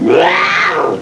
collision.wav